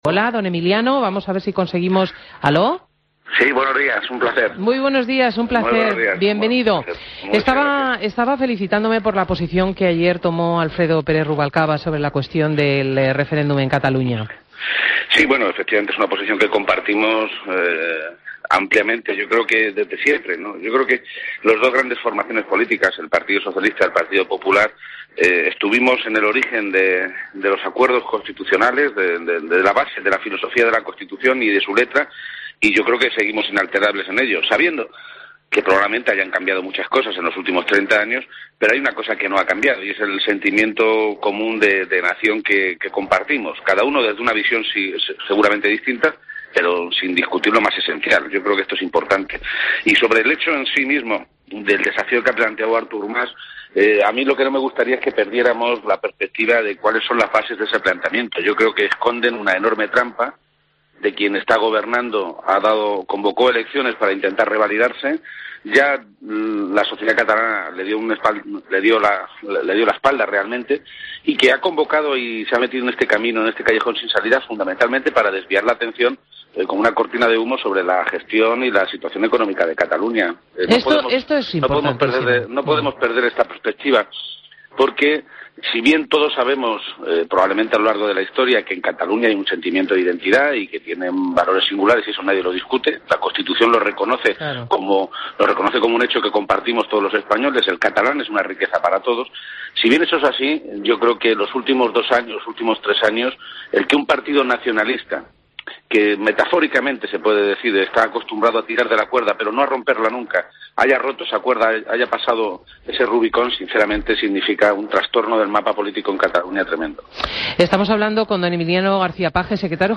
Entrevista a Emiliano García Page en Fin de Semana COPE